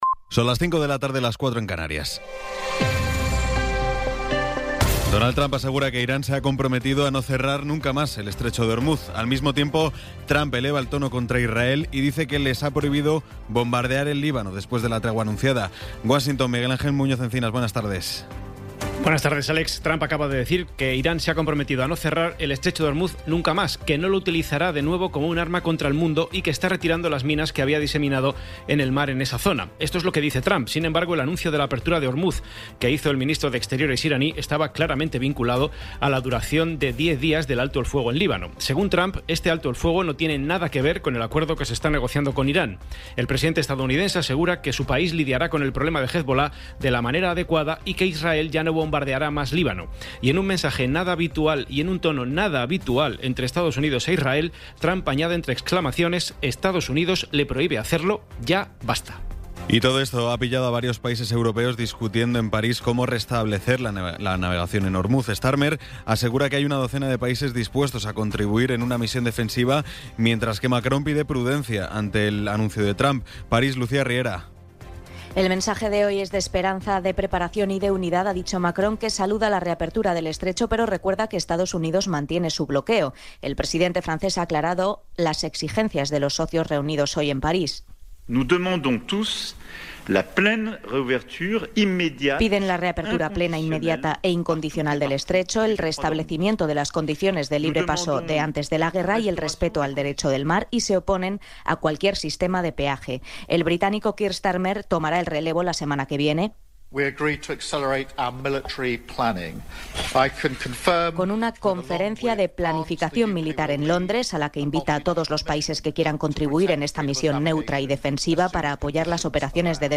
Resumen informativo con las noticias más destacadas del 17 de abril de 2026 a las cinco de la tarde.